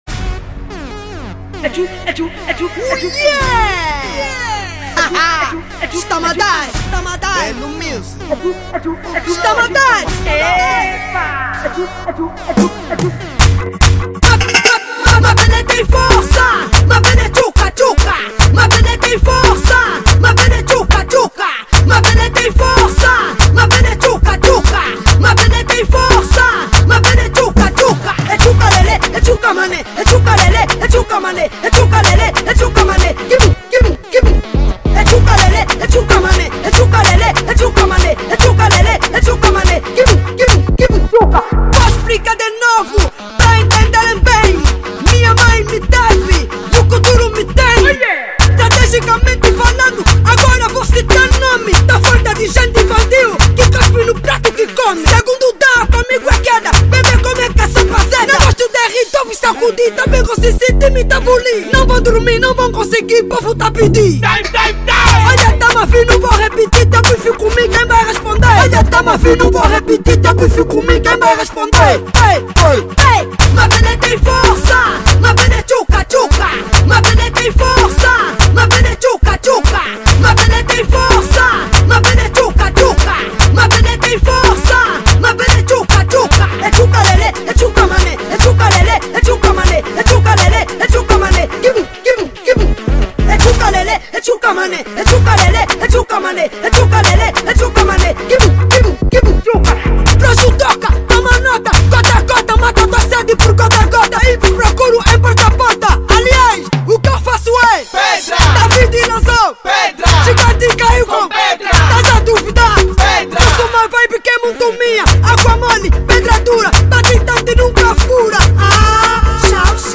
Género: Kuduro